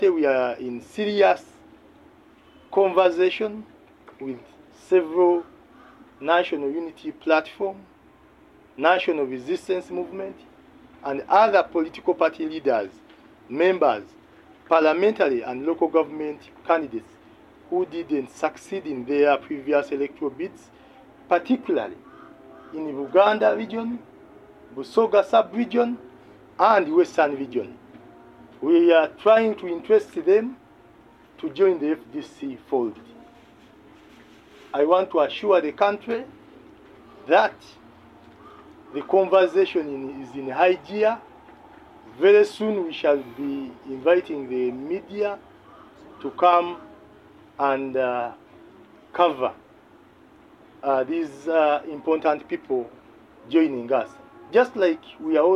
during a press briefing at the FDC headquarters in Najjanankumbi.